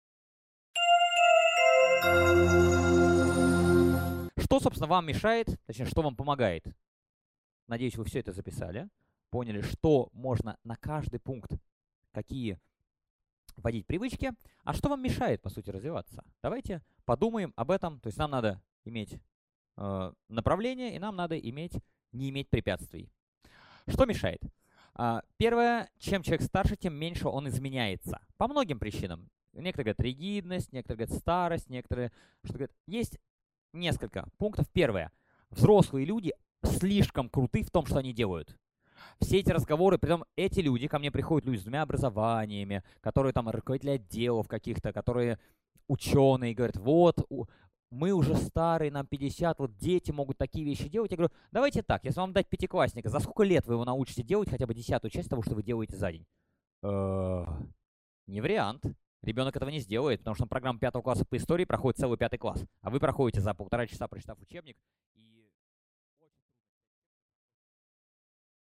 Аудиокнига Что мешает развитию?